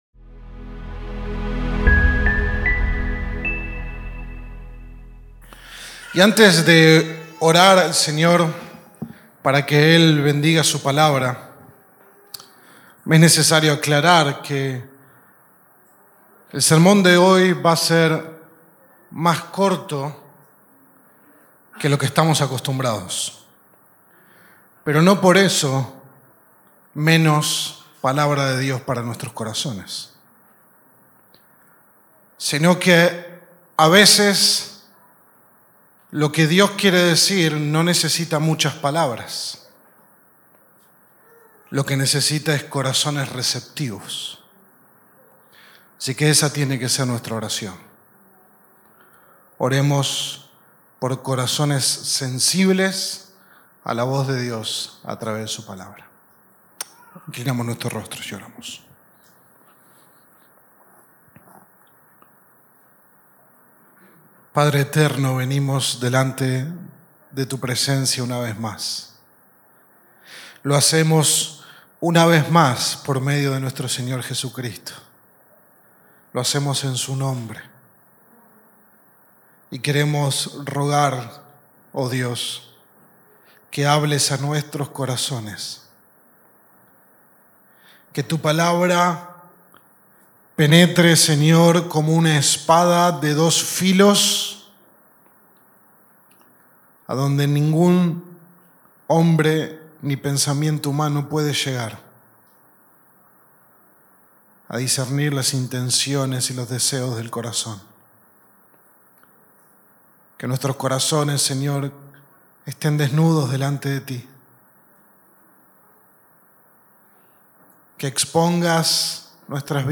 Sermón 32 de 33 en Sermones Individuales
Sermon-28-DIC-MP3.mp3